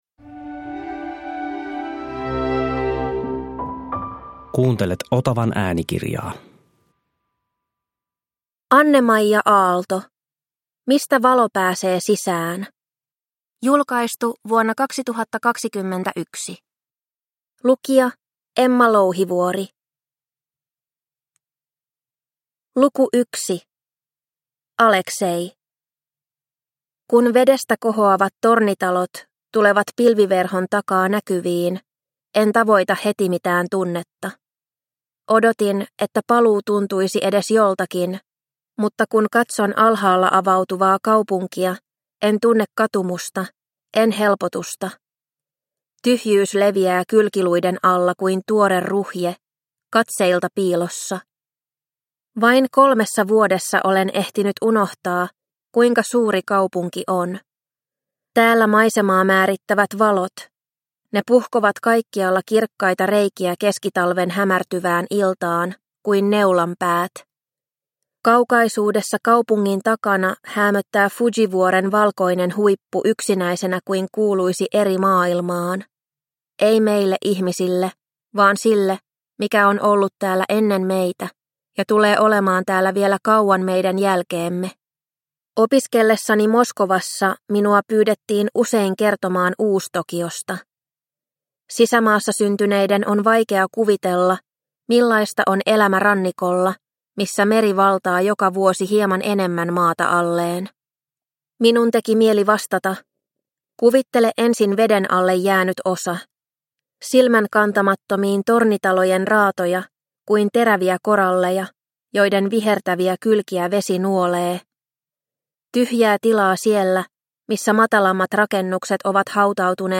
Mistä valo pääsee sisään – Ljudbok – Laddas ner